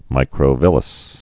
(mīkrō-vĭləs)